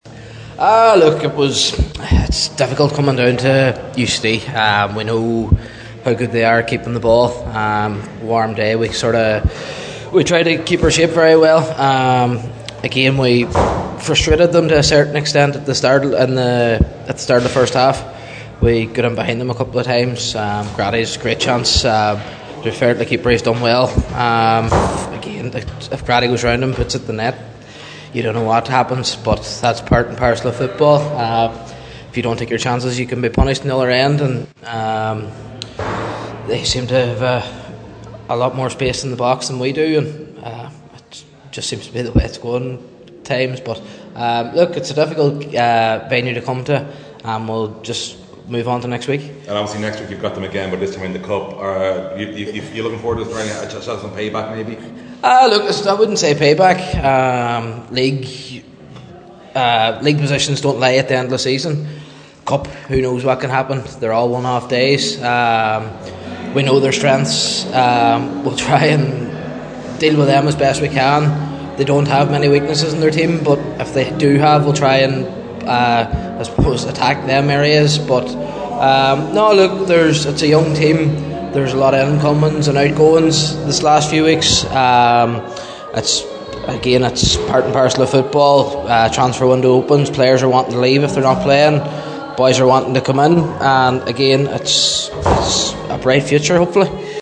After the loss at the Belfield Bowl